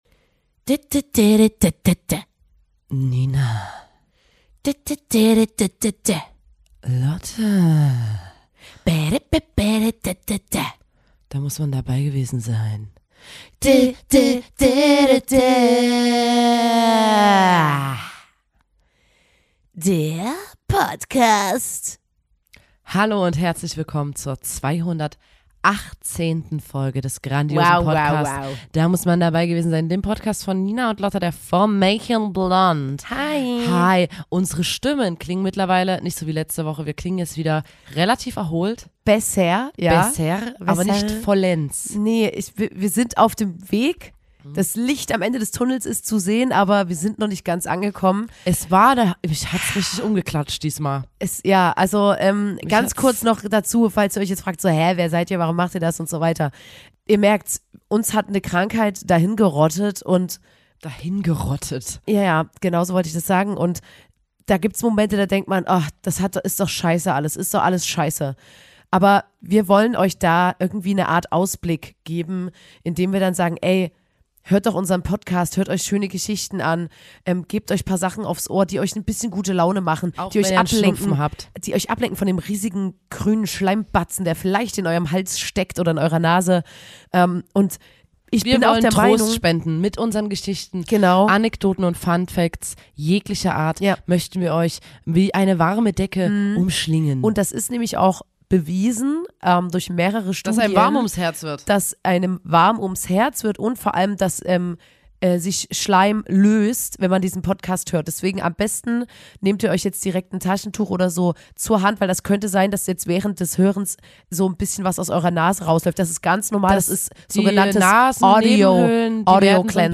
Die Schallwellen der Schwestern-Stimmen massieren euren gewundenen Muskelschlauch auf wohltuende Art und Weise.